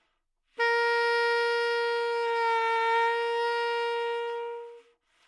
萨克斯中音单音（吹得不好） " 萨克斯中音A4不好的音调不好的音色
描述：在巴塞罗那Universitat Pompeu Fabra音乐技术集团的goodsounds.org项目的背景下录制。单音乐器声音的Goodsound数据集。 instrument :: sax_tenornote :: A＃octave :: 4midi note :: 58microphone :: neumann U87tuning reference :: 442.0goodsoundsid :: 5123 故意扮演坏音调的坏音色的例子